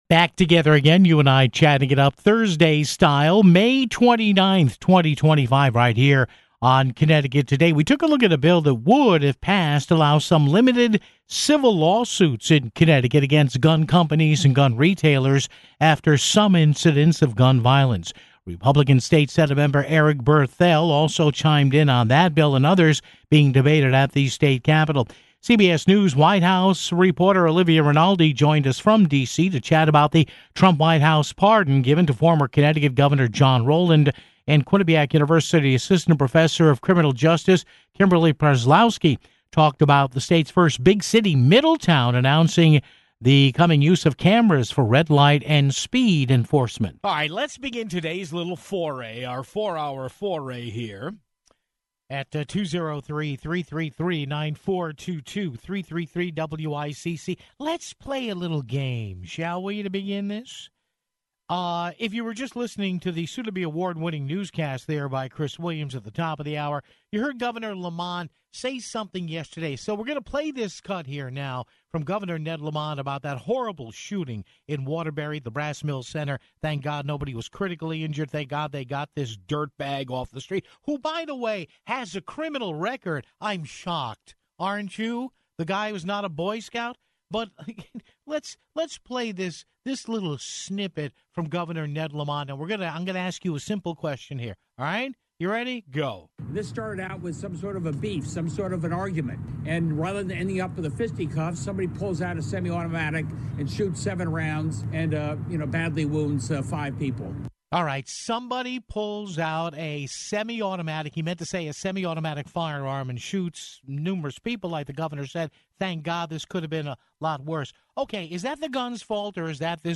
GOP State Sen. Eric Berthel also chimed in on that bill and others being debated at the State Capitol (15:44).